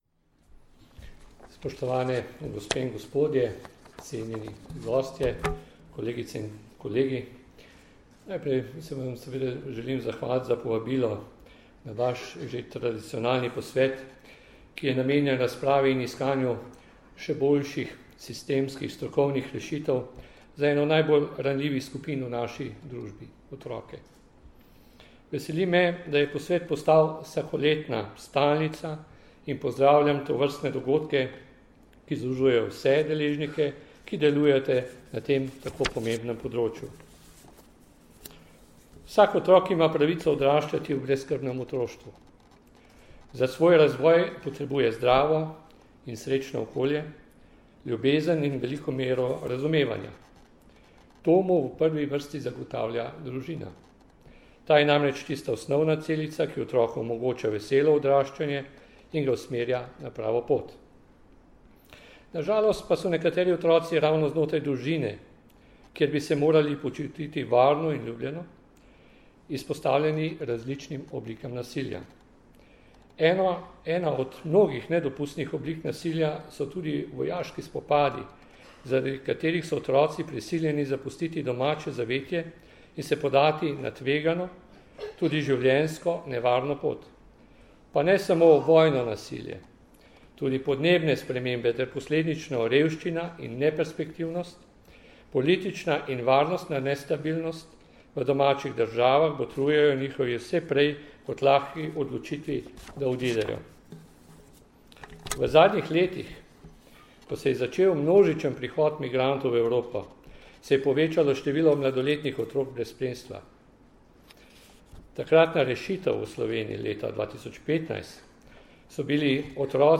V Kongresnem centru Brdo, Predoslje, se je danes, 6. aprila 2017, pod naslovom V imenu otroka začel dvodnevni posvet na temo problematike poznavanja otroka za kvalitetnejšo obravnavo v postopkih, ki ga organizirata Policija in Društvo državnih tožilcev Slovenije v sodelovanju s Centrom za izobraževanje v pravosodju.
Zvočni posnetek nagovora Boštjana Šefica (mp3)